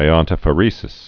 (ī-ŏntə-fə-rēsĭs)